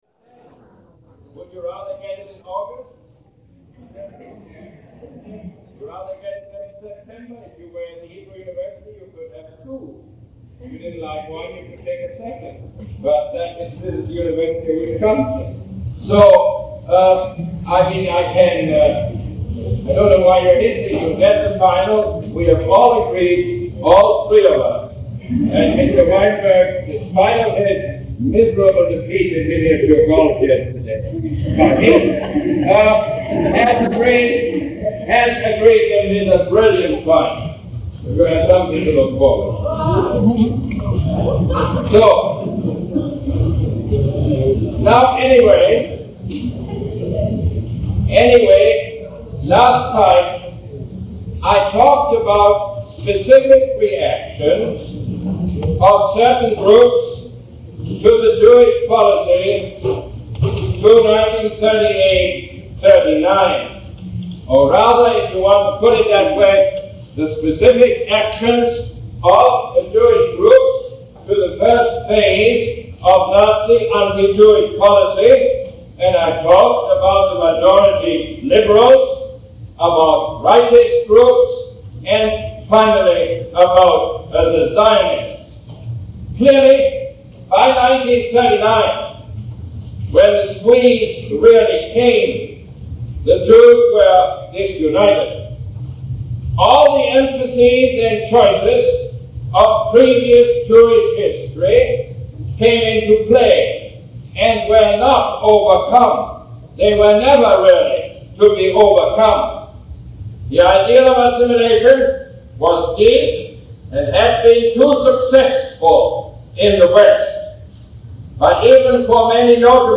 Lecture #27 - May 19, 1971